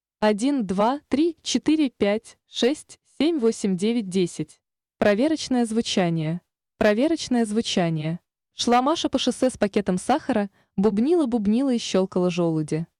Сформировал в яндекс переводчике звуковой файл, в котором сделал много шипящих, свистящих и бубнящих звуков, закинул его на плейер. Плейер подключил к SSM, а выход SSM к линейному входу звуковой карты компьютера. На компе звук записывал с помощью Adobe Audition.
2. Резистор 200кОм = 15кОм, компрессия 2:1